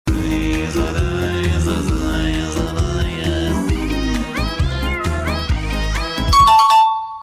loud correct buzzer | credits sound effects free download